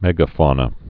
(mĕgə-fônə)